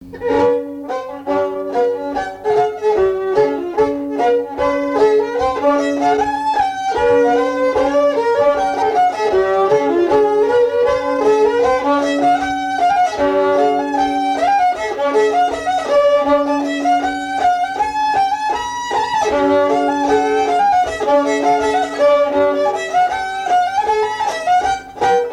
danse : scottich trois pas
Pièce musicale inédite